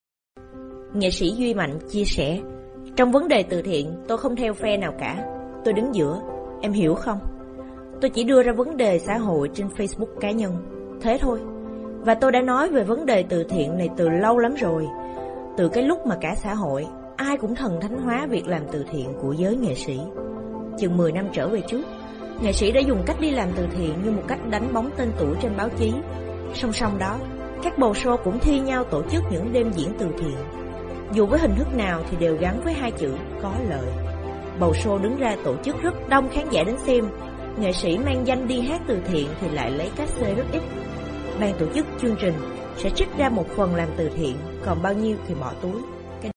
当前位置：首页> 样音试听 >优选合集 >外语配音合集 >越南语配音